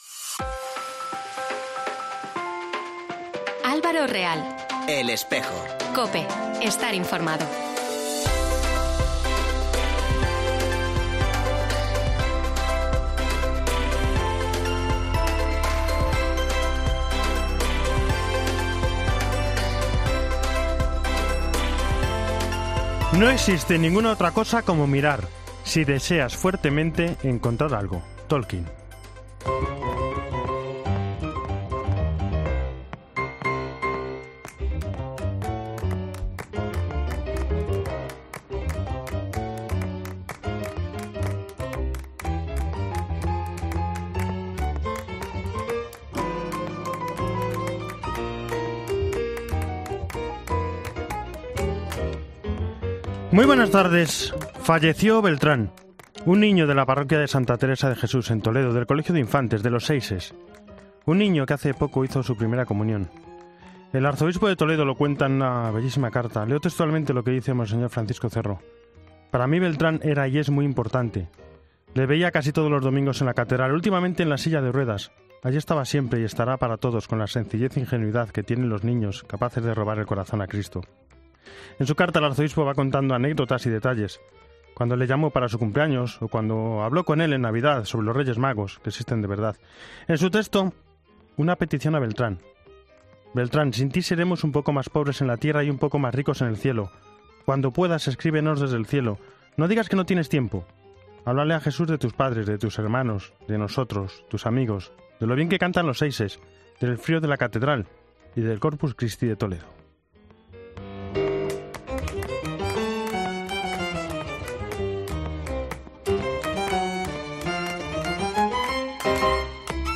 entrevista y reportaje.